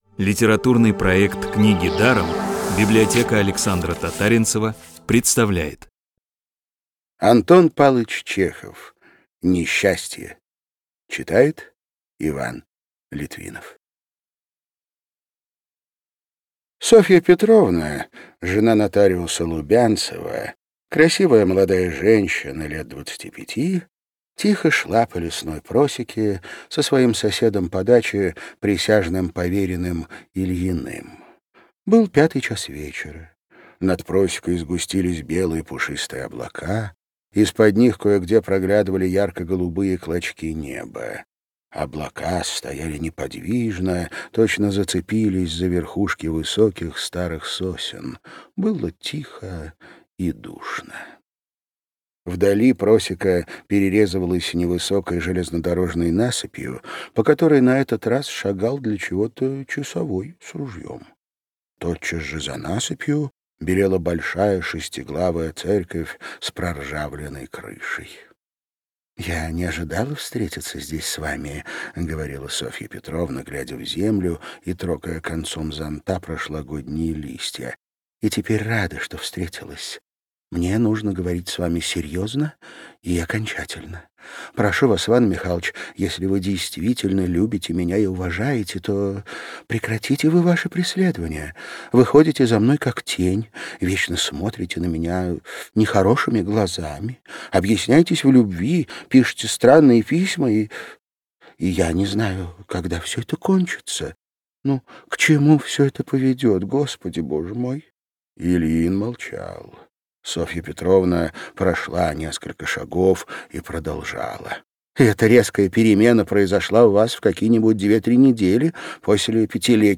Бесплатная аудиокнига «Несчастье» от Рексквер.
Классическую литературу в озвучке «Рексквер» легко слушать и понимать благодаря профессиональной актерской игре и качественному звуку.